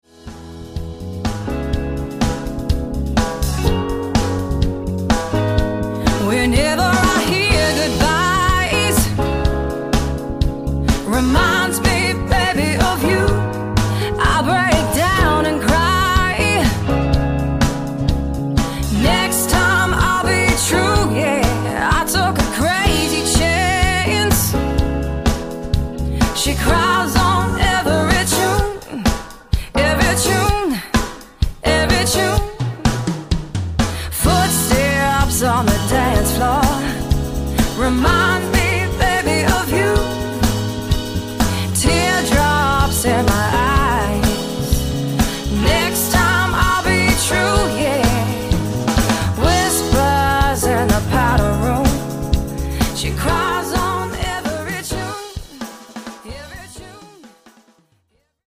Corporate and Wedding Cover Band Hire Melbourne